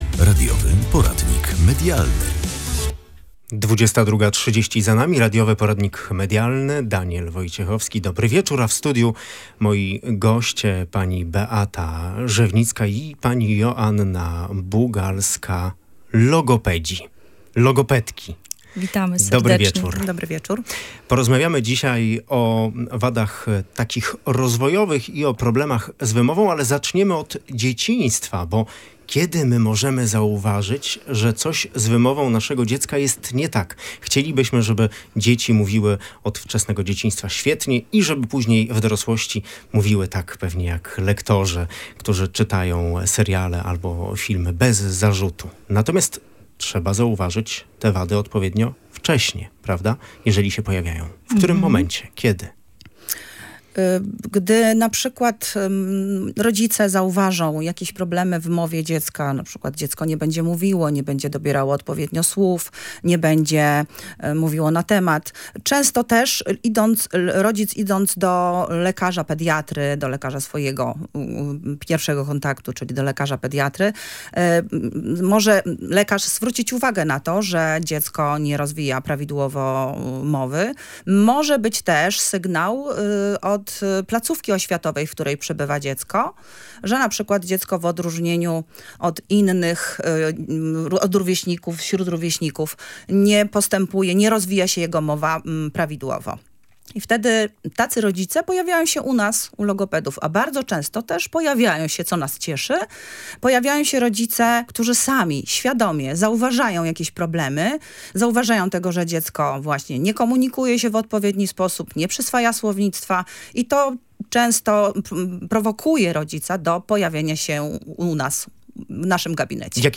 W audycji gościliśmy logopedki